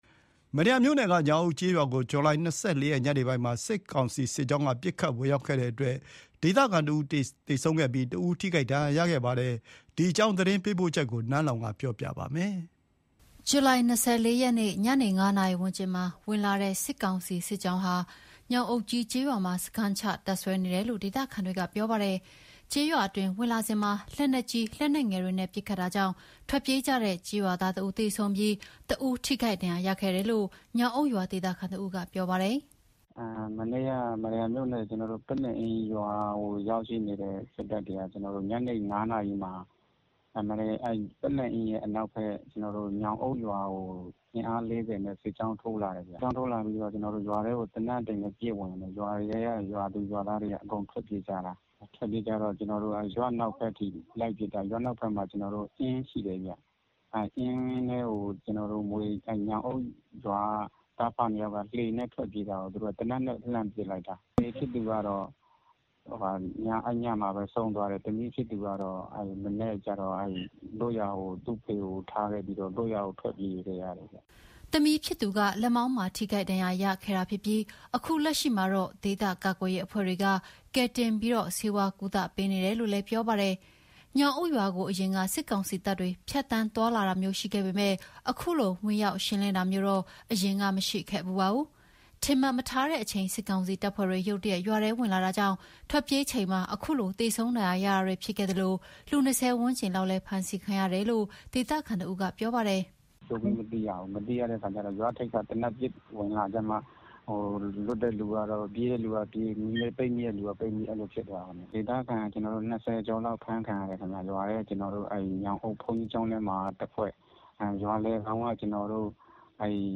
ညောင်အုပ်ရွာနဲ့ အနီးတဝိုက်ဟာ စစ်ကောင်စီနဲ့ ဒေသကာကွယ်ရေးတပ်တွေကြား ထိတွေ့တိုက်ပွဲ ဖြစ်လေ့ မရှိတဲ့ ဒေသဖြစ်တယ်လို့လည်း ပြောဆိုကြပါတယ်။ စစ်ကြောင်းဝင်လာချိန် ထွက်ပြေးကြရတဲ့ အခြေအနေ ကို ဒေသခံအမျိုးသမီးတဦးကလည်း ခုလိုပြောပြပါတယ်။